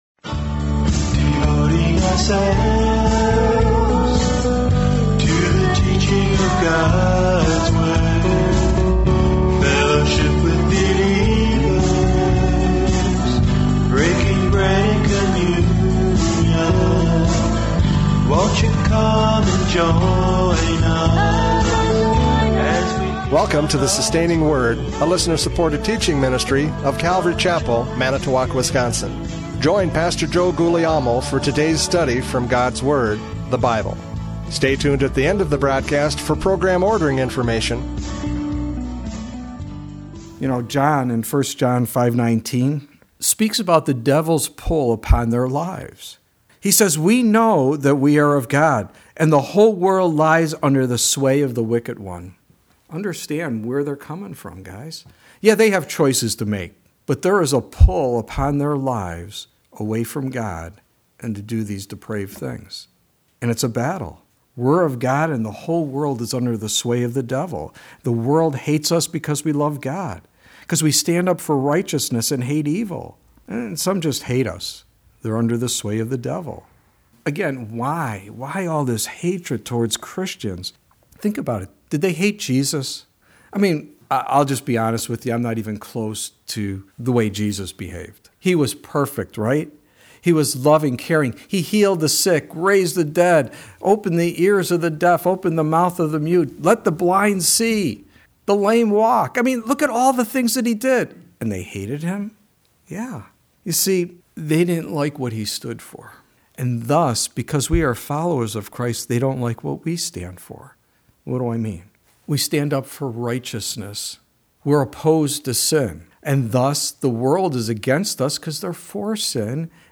John 15:18-27 Service Type: Radio Programs « John 15:18-27 The World’s Response!